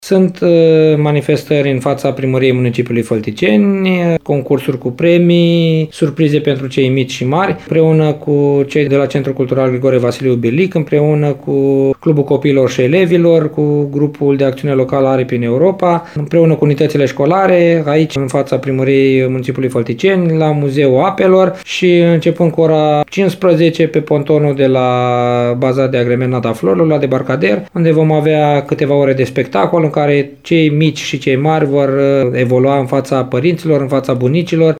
Edilul șef CĂTĂLIN COMAN detaliază.